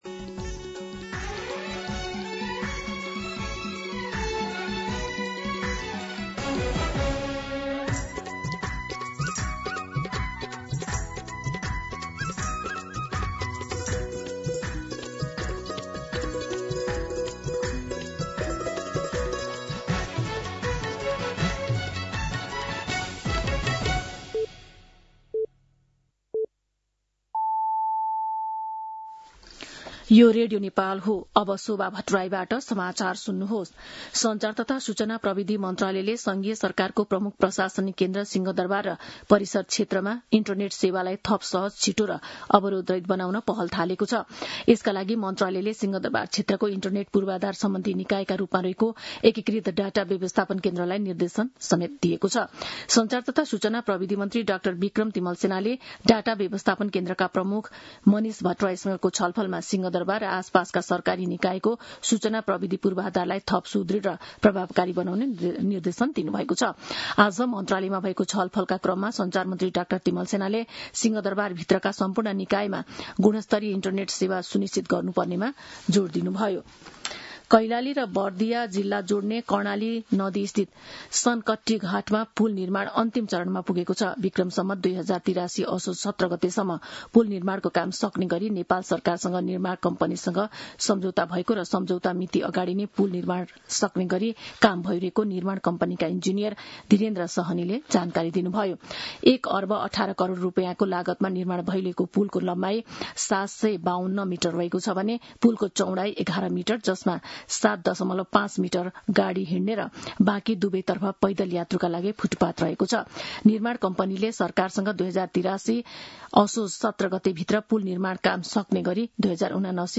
मध्यान्ह १२ बजेको नेपाली समाचार : ३० चैत , २०८२